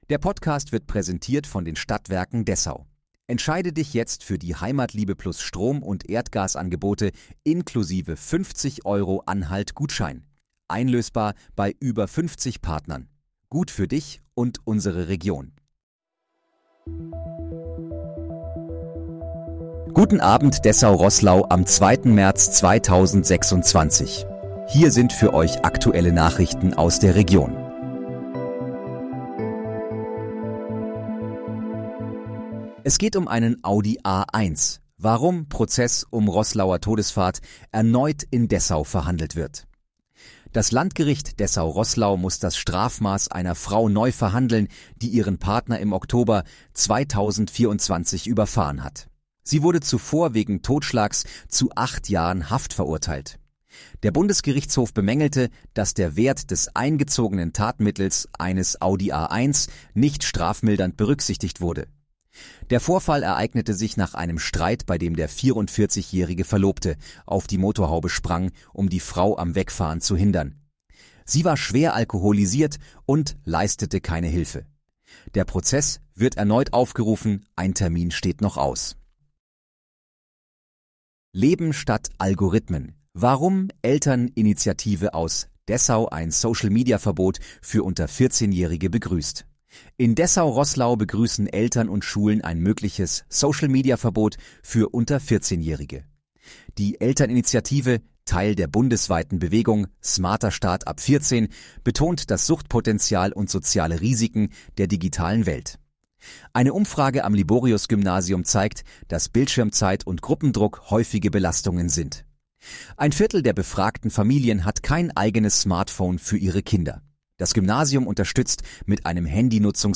Guten Abend, Dessau-Roßlau: Aktuelle Nachrichten vom 02.03.2026, erstellt mit KI-Unterstützung